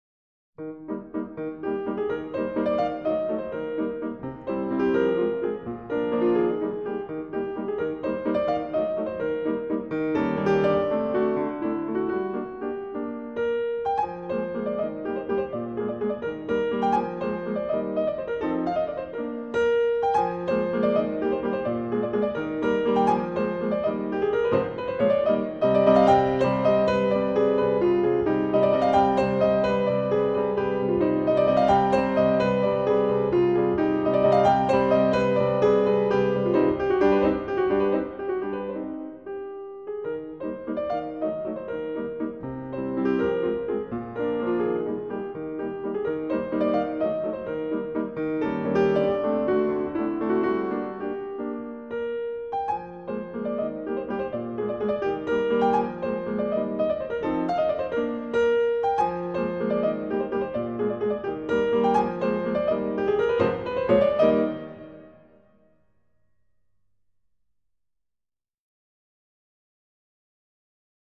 0283-钢琴名曲圆舞曲.mp3